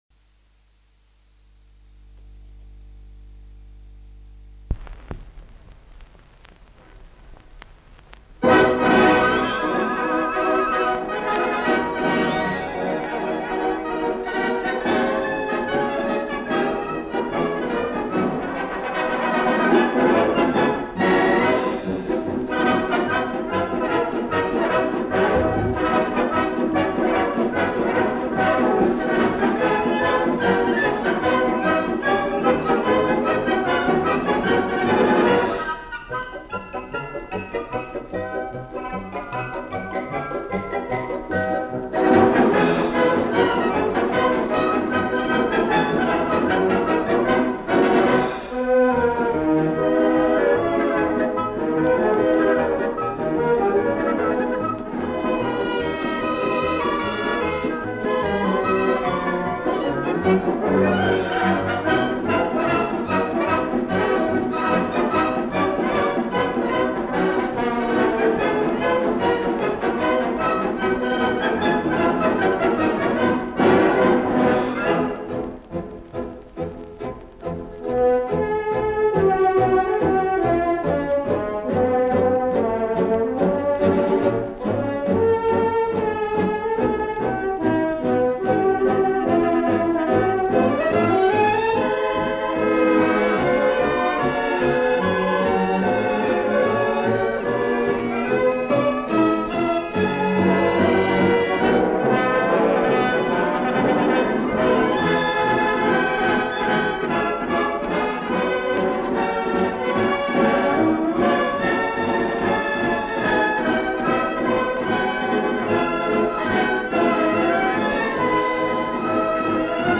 (марш)